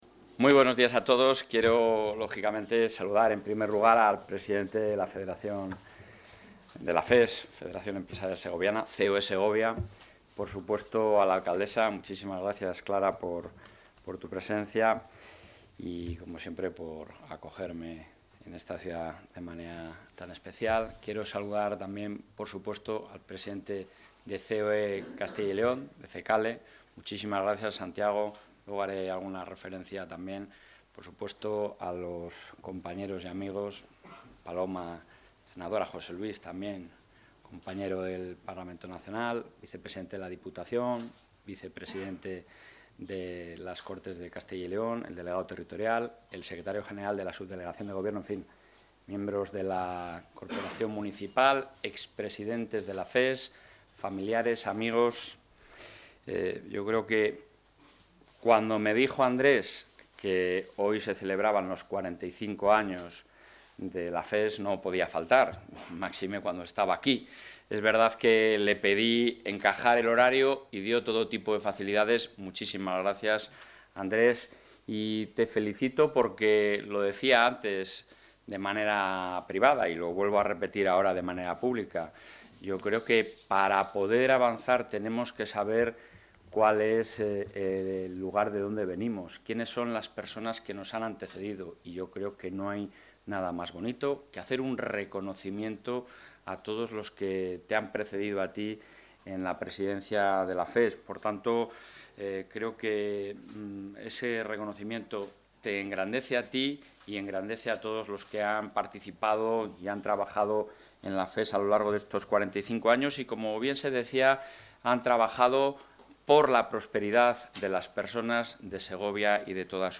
Declaraciones del presidente de la Junta.